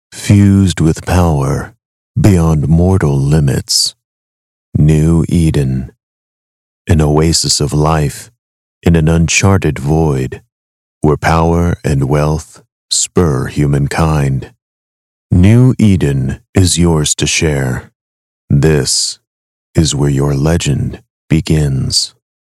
美式英语中年低沉 、激情激昂 、大气浑厚磁性 、沉稳 、娓娓道来 、科技感 、积极向上 、时尚活力 、神秘性感 、调性走心 、素人 、低沉 、男专题片 、宣传片 、纪录片 、广告 、飞碟说/MG 、课件PPT 、工程介绍 、绘本故事 、动漫动画游戏影视 、旅游导览 、微电影旁白/内心独白 、60元/百单词男英113 美式英语 英语教材教辅儿童学英语课件 低沉|激情激昂|大气浑厚磁性|沉稳|娓娓道来|科技感|积极向上|时尚活力|神秘性感|调性走心|素人|低沉
男英113 美式英语 英语课件英语启蒙教辅 低沉|激情激昂|大气浑厚磁性|沉稳|娓娓道来|科技感|积极向上|时尚活力|神秘性感|调性走心|素人|低沉